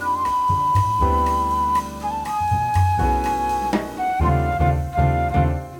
This is another jazz flute stinger instrumental.